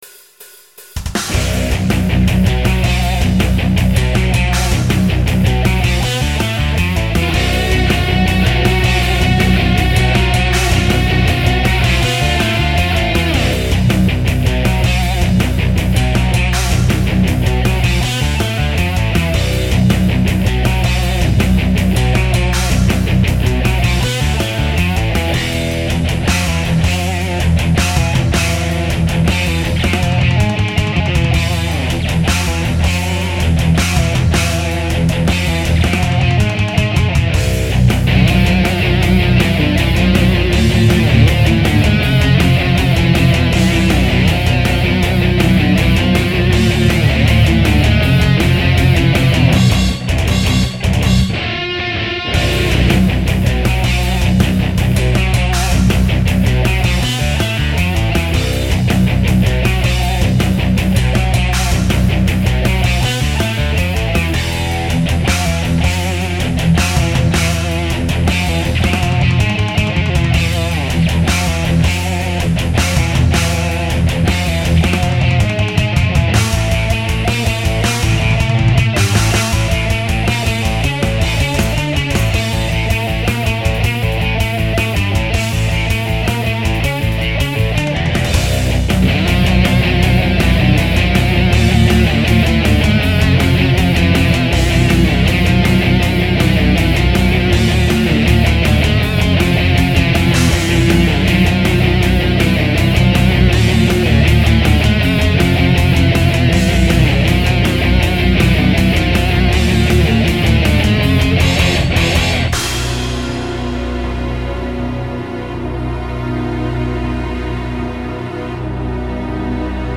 Vos Compos Metal
J'ai conscience que c'est un peu monotone sans le chant, mais une version avec chant est à venir rapidement !
Sinon, c'est tres bien joué. Le son est bon.
je trouve que t'aurai du mettre la grosse caisse plus en avant, genre blast beat :diable:
La batterie c'est du Dfh...classique quoi !
C'est vrai qu'un peu plus de coffre serait pas mal, d'autant que le rythme est un peu toujours le même !
Perso quand je compose, je pense faire du death...et pi après on me dit que je fais du metal prog...